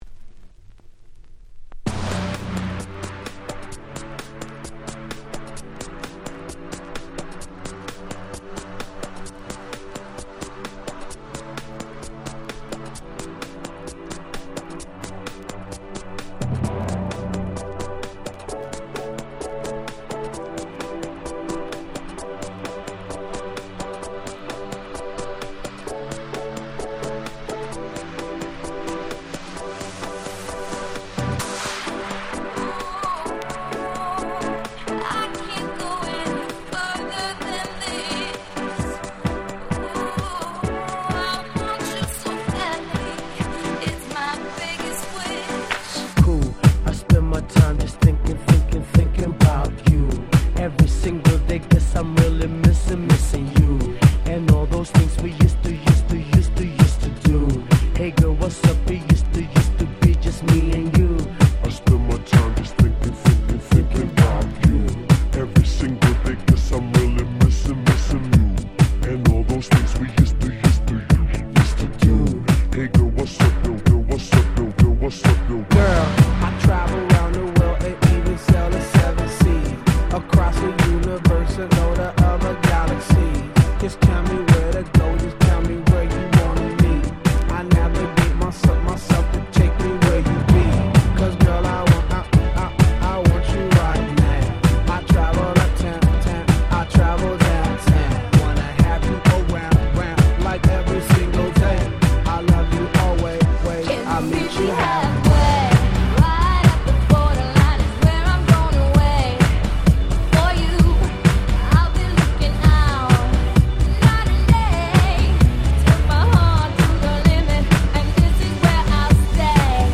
09’ Super Hit R&B / Hip Hop !!
EDMとクロスオーバー気味だった頃の彼らのスーパーヒット！！